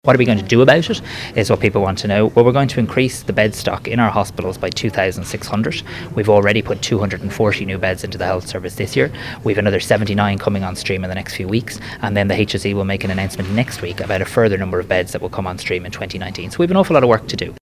But Minister Simon Harris says this is being addressed: